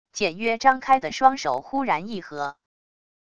简约张开的双手忽然一合wav音频